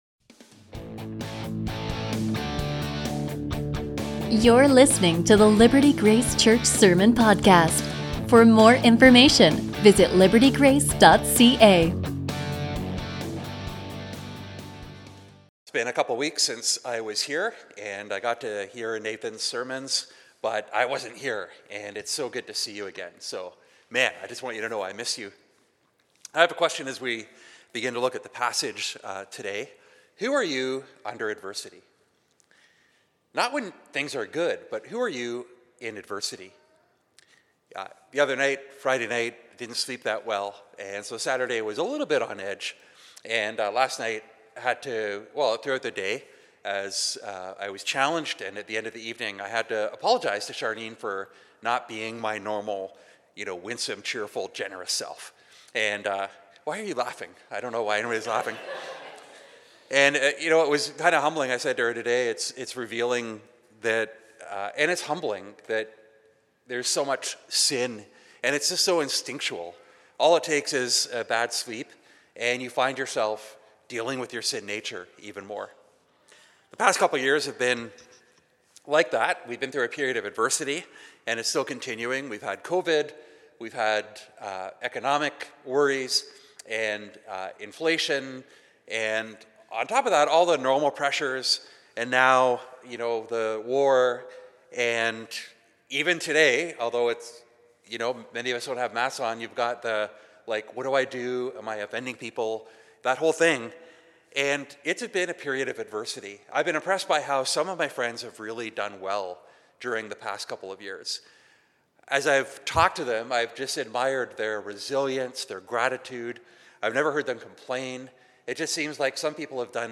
A sermon from Mark 14:43-52